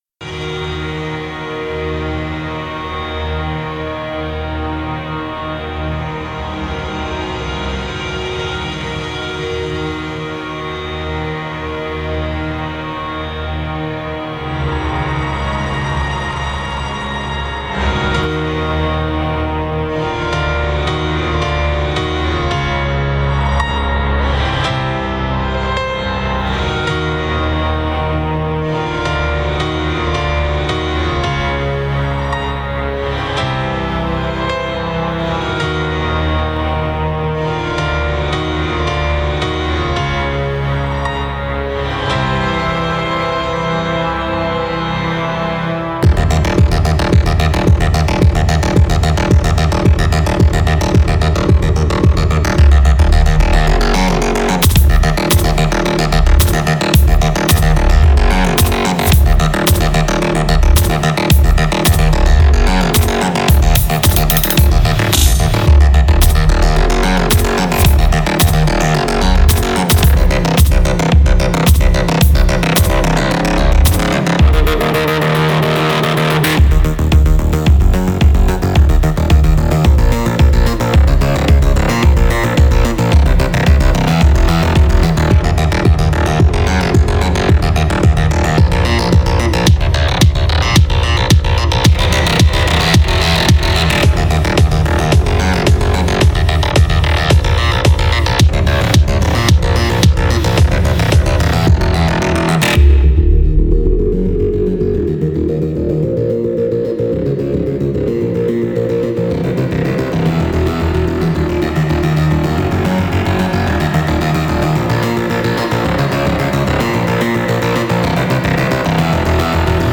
le célèbre duo
French Touch